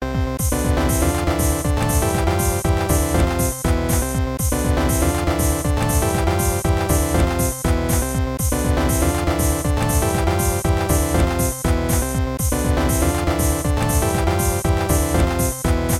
This audio soundtrack was created with aviary's music creator.
Techno Melody.wav